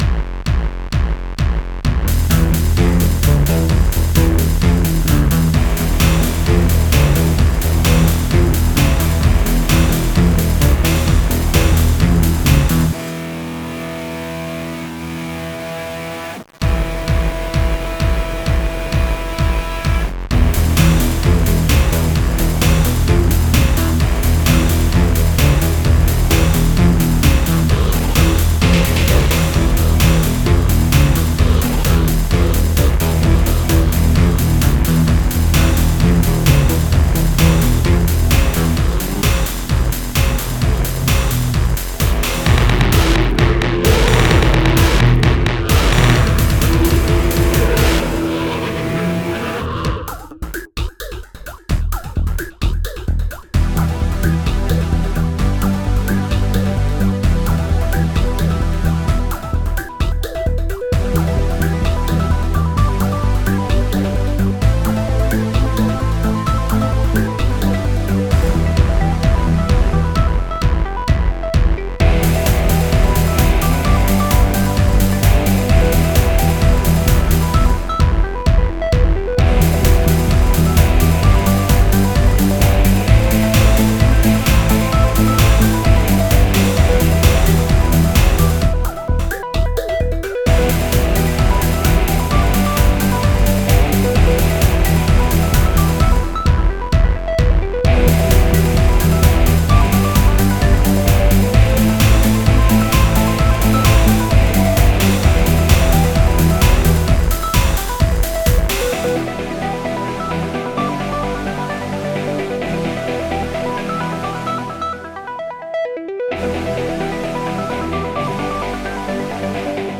Two ideas in one, the second and best part starts at 50 seconds, the closest thing to Country and Western that i'll ever make :). Don't worry it of course includes the mandatory synth and drums.